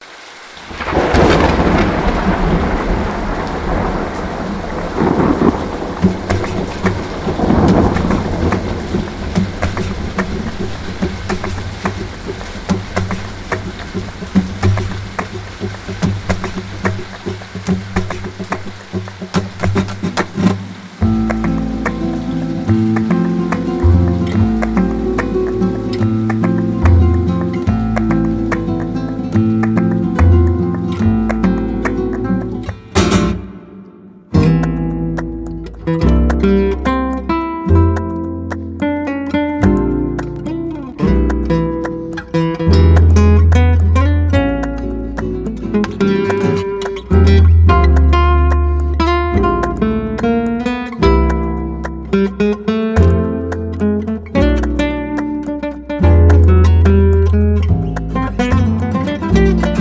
flamenco-gitarre, laúd, perc.
kontrabass, e-bass
percussion